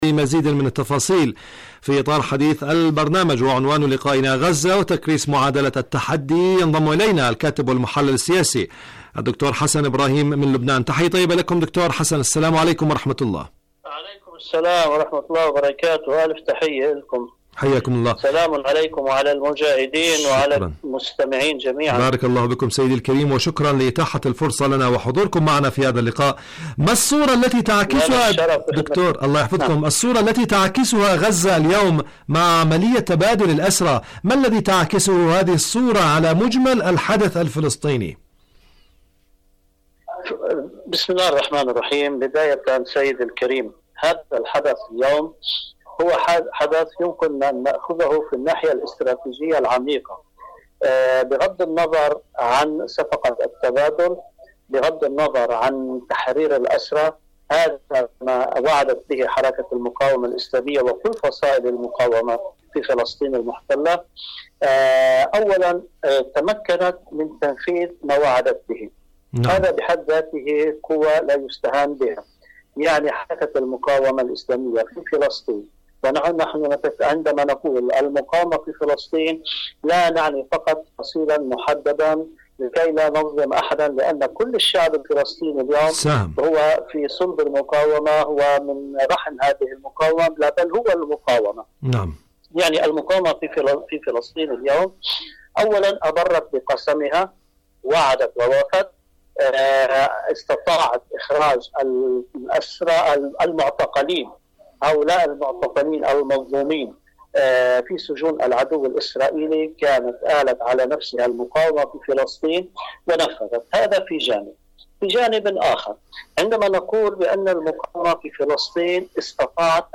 برنامج فلسطين اليوم مقابلات إذاعية